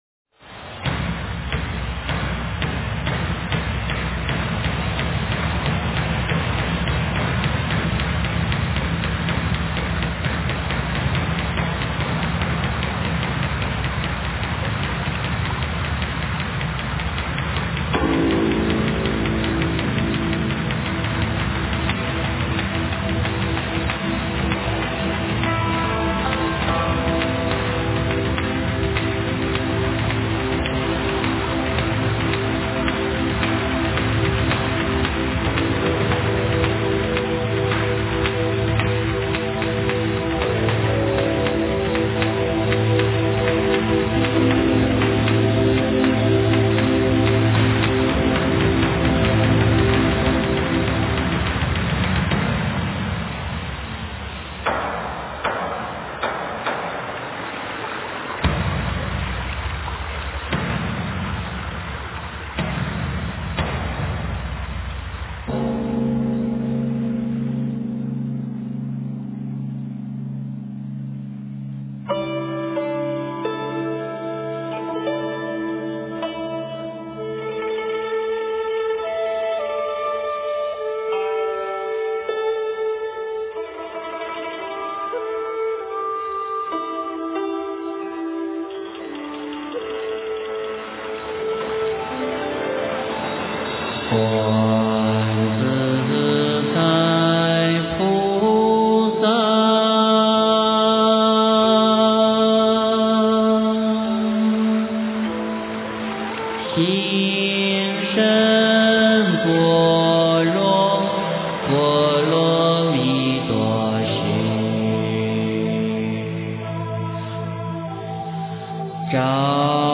诵经
佛音 诵经 佛教音乐 返回列表 上一篇： 观音禅定 下一篇： 观音圣号 相关文章 腊八节的故事--黄慧音 腊八节的故事--黄慧音...